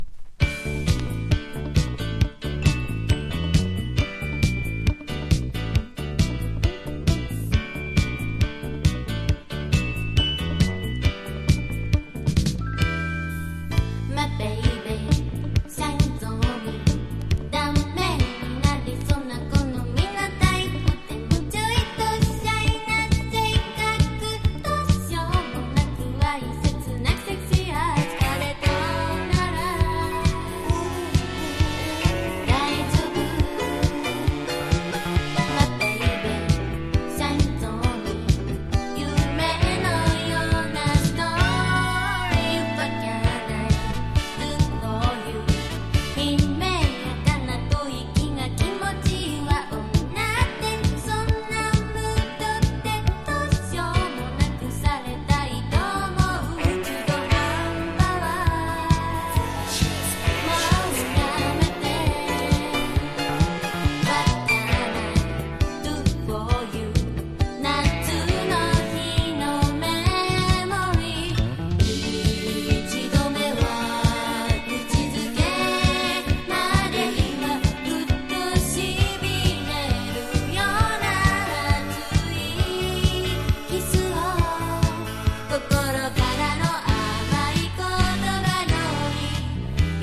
もっと評価されても良い独特のヴォーカルがクセになります。
和モノ / ポピュラー